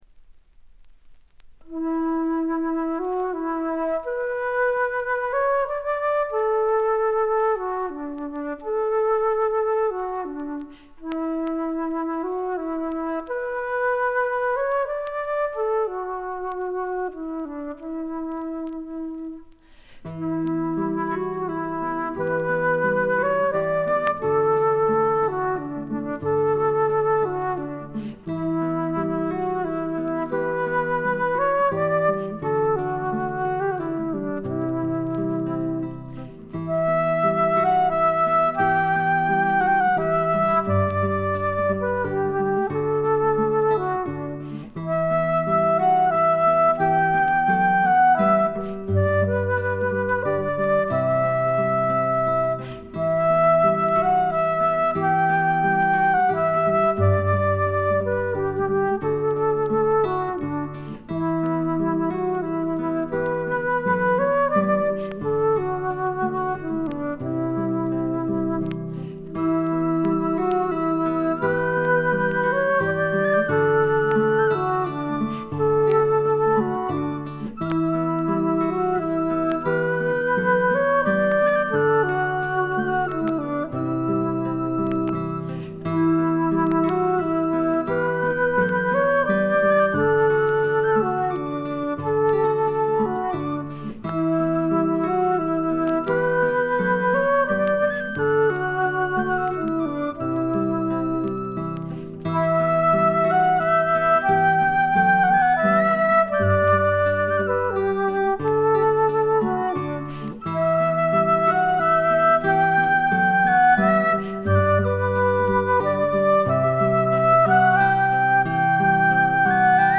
バックグラウンドノイズ、チリプチ多め大きめ。
オランダのトラッド・フォーク・グループ
試聴曲は現品からの取り込み音源です。